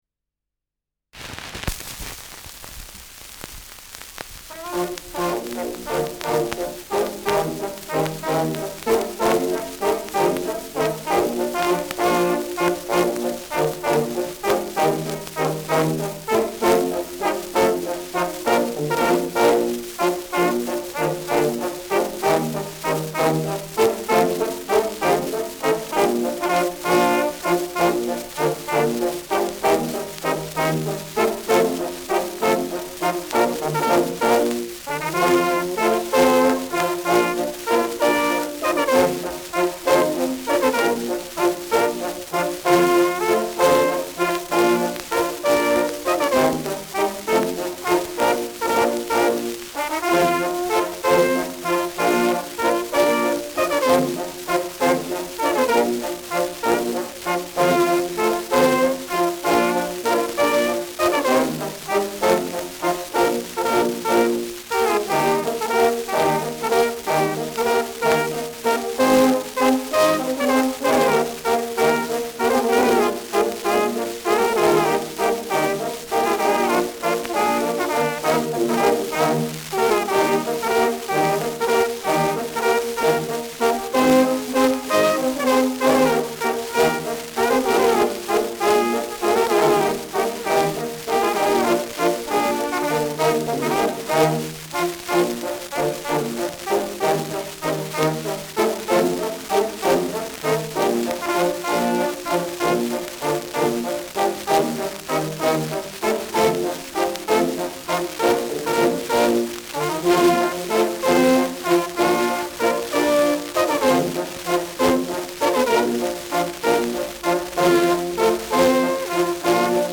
Schellackplatte
Tonrille: Kratzer 4 Uhr Stärker : Kratzer 8-9 Uhr Leicht
Stärkeres Grundrauschen